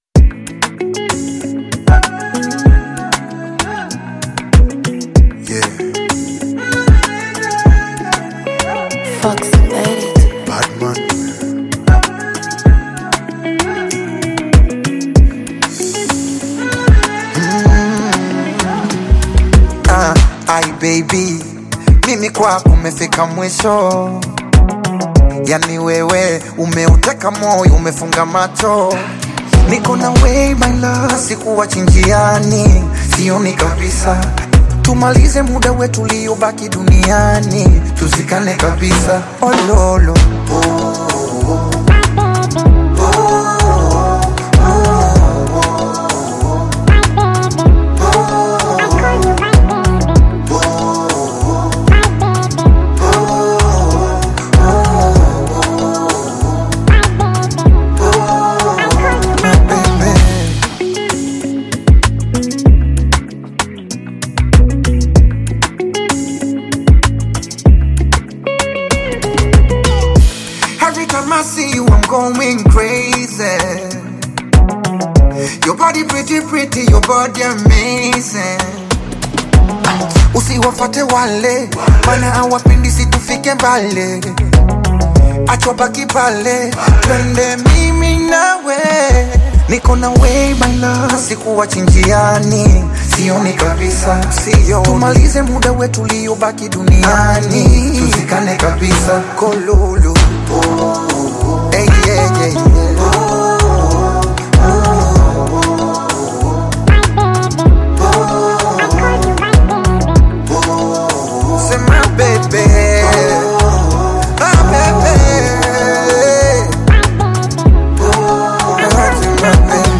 R&B with African beats